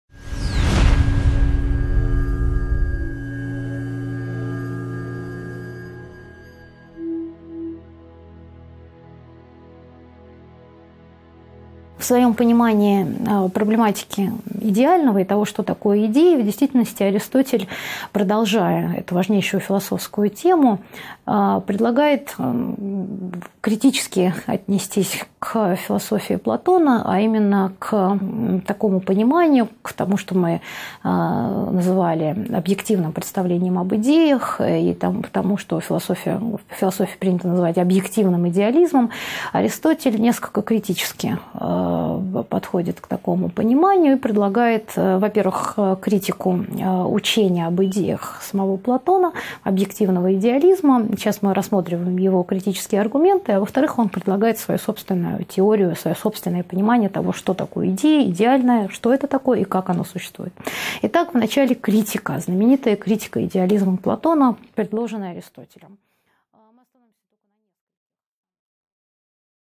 Аудиокнига 2.10 Аристотель: критика идеализма Платона | Библиотека аудиокниг